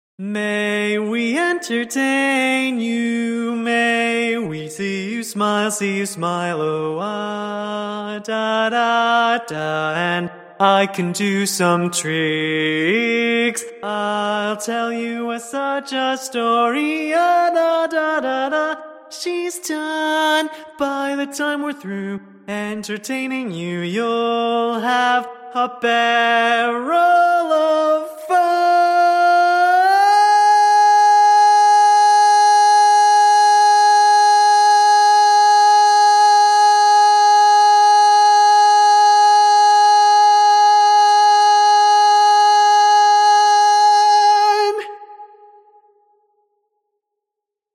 Key written in: A♭ Major
Type: Female Barbershop (incl. SAI, HI, etc)
Each recording below is single part only.